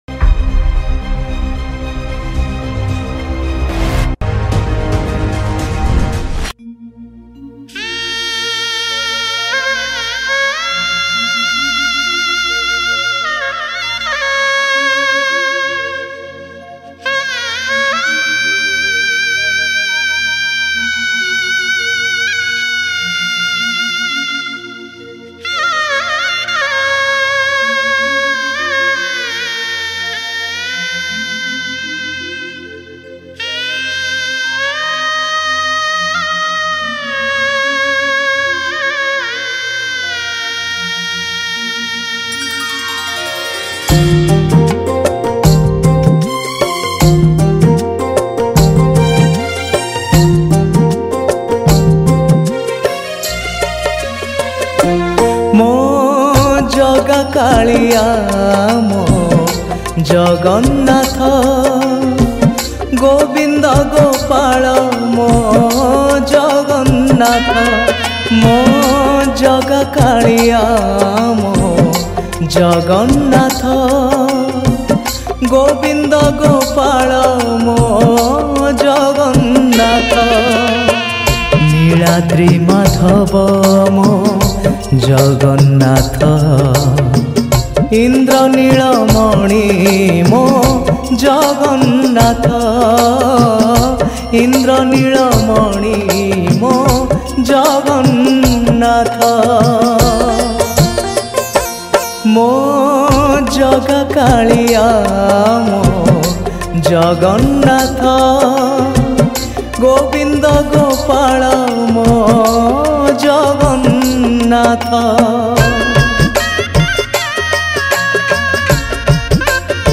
Tabla